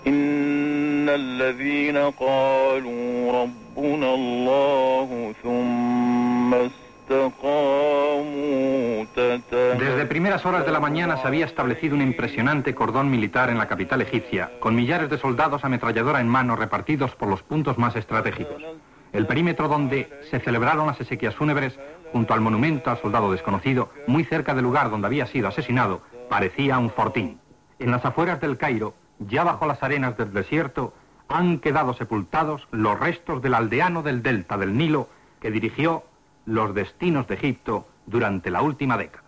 Crònica de l'enterrament del president egipci Anwar el-Sadat a El Caire
Informatiu